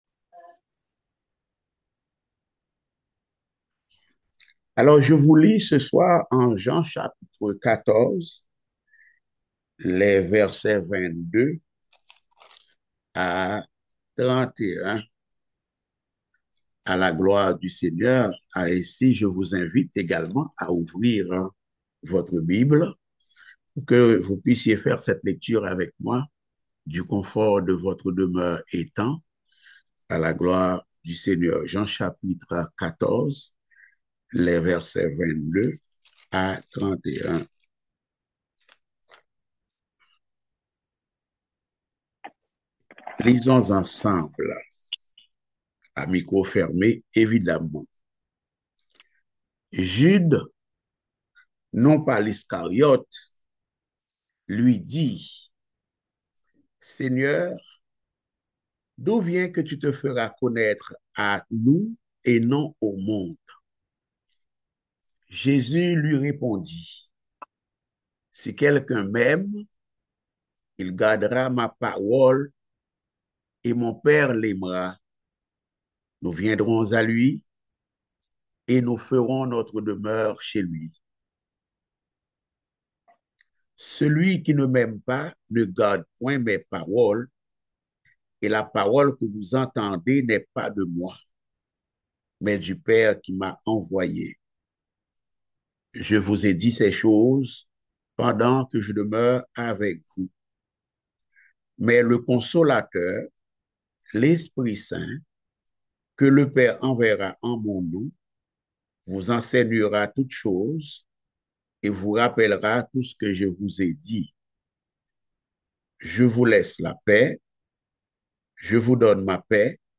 Passage: Jean 14. 22-31 Type De Service: Études Bibliques « Les derniers entretiens et discours de Jésus avec ses disciples avant sa mort.